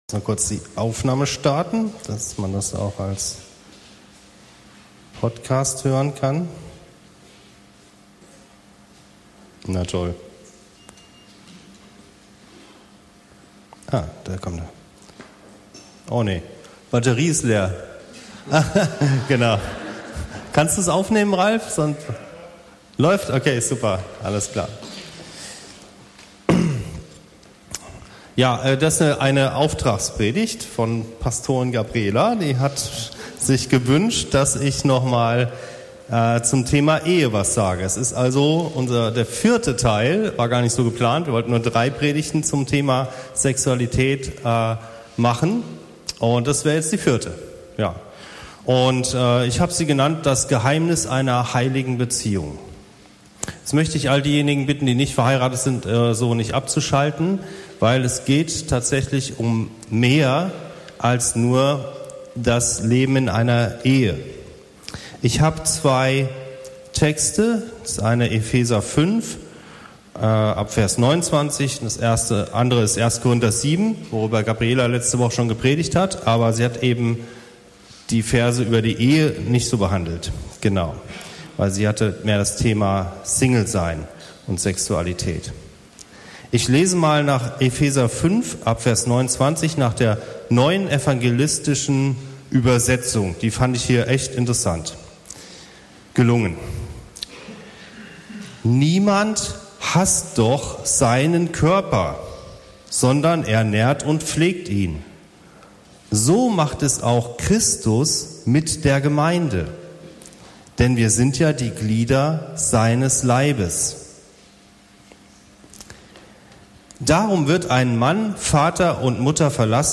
Sexualität, Teil 4: Ehe – Das Geheimnis einer heiligen Beziehung ~ Anskar-Kirche Hamburg- Predigten Podcast